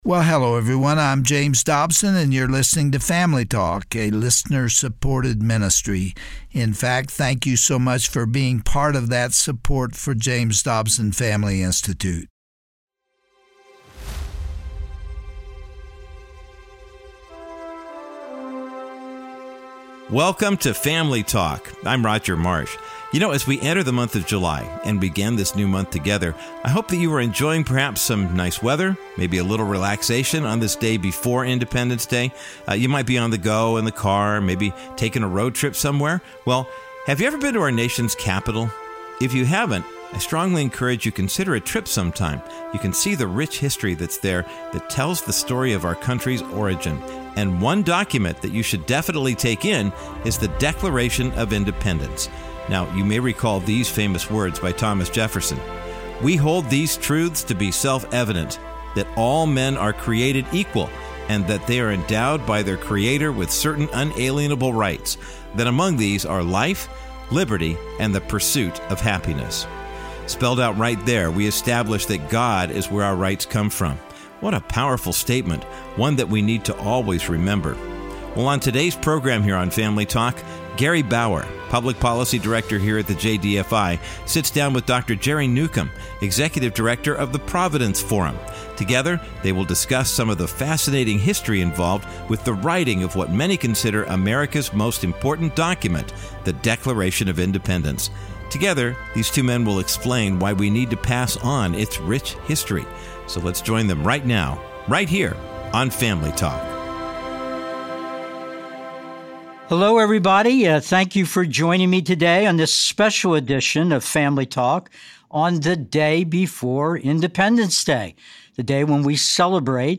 Host Gary Bauer